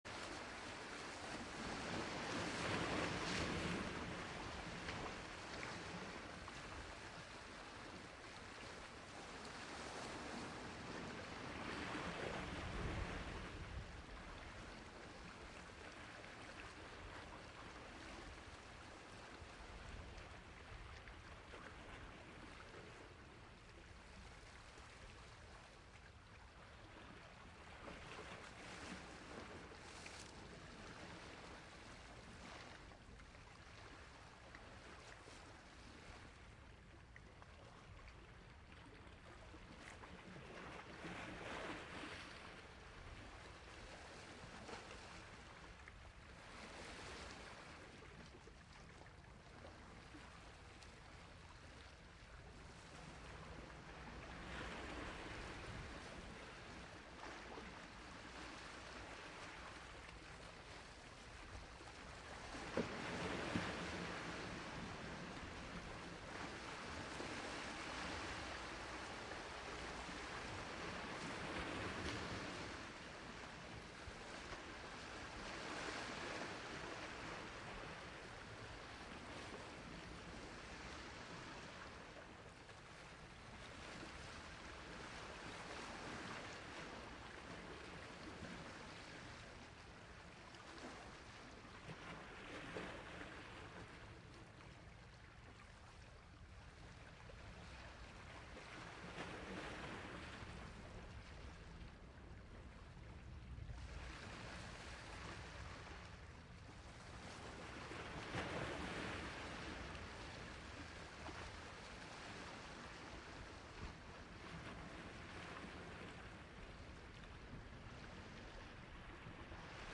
seaside-soft-waves-24405.mp3